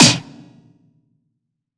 SNARE 070.wav